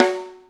Snare 17.wav